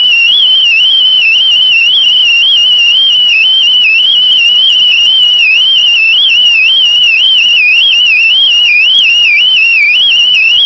A simple voltage-to-frequency converter can be used to "upconvert" the (sub-sonic) frequencies of a person's heart beat or breathing to something a